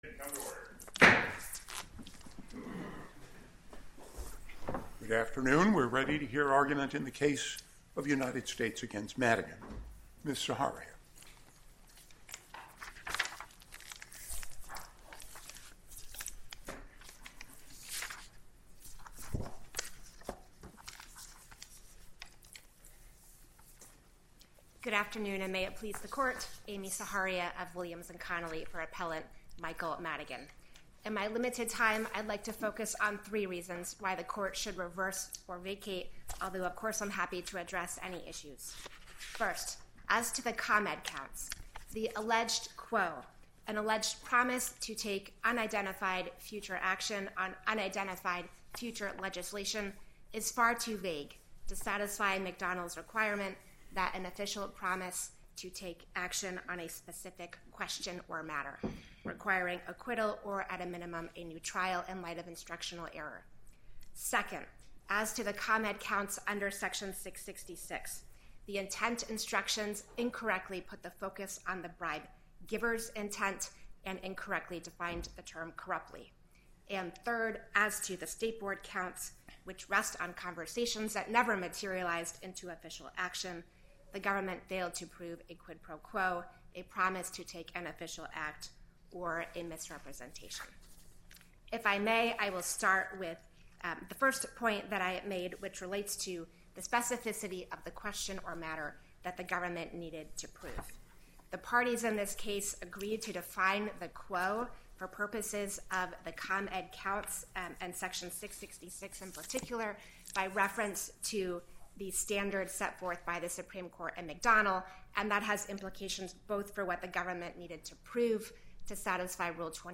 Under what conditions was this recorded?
* Click here for the full audio of yesterday’s proceedings.